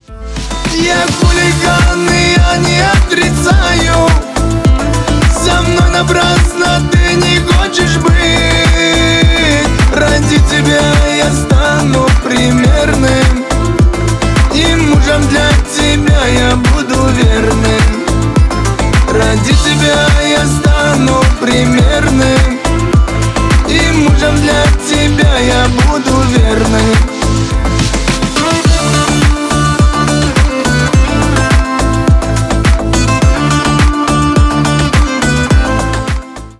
• Качество: 320, Stereo
гитара
кавказские